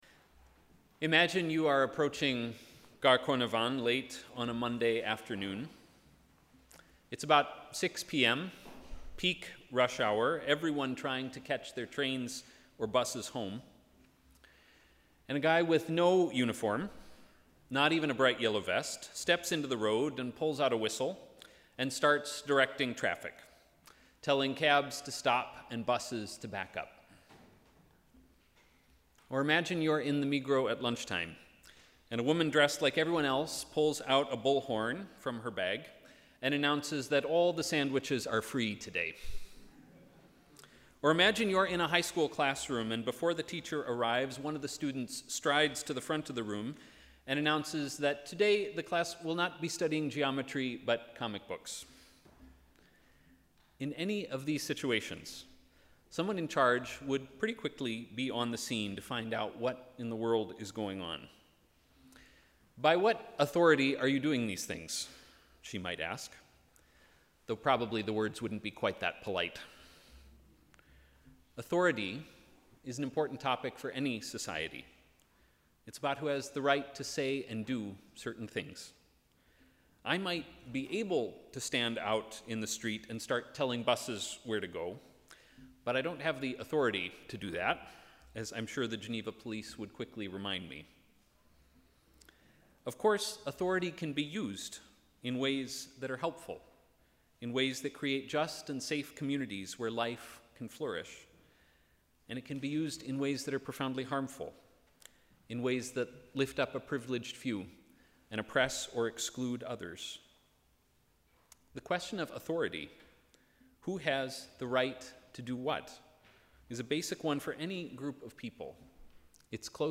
Sermon: ‘By whose authority?’